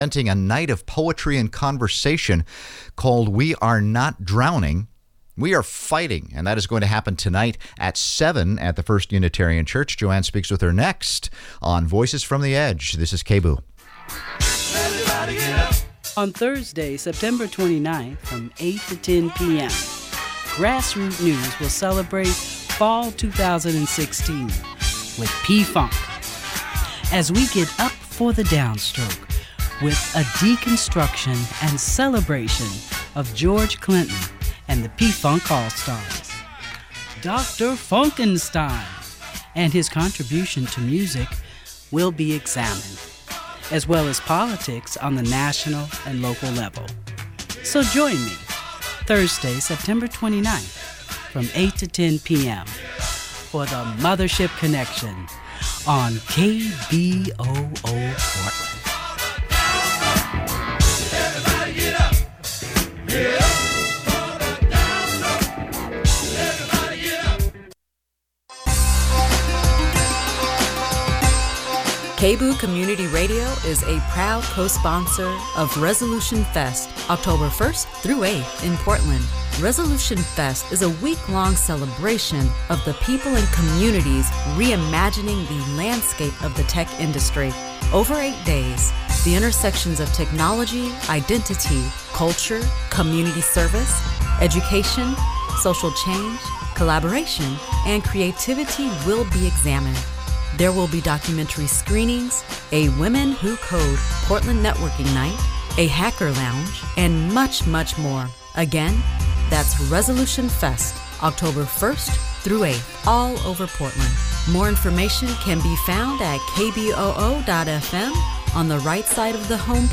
Progressive talk radio from a grassroots perspective
With an hour to invest, the call-in format engages listeners in meaningful conversations about crucial issues like racial disparity, government accountability, environmental justice and politics on local, state and national levels.